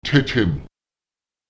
Lautsprecher te(m)tem [ÈtEtEm] dreiundsechzig („siebensieben“)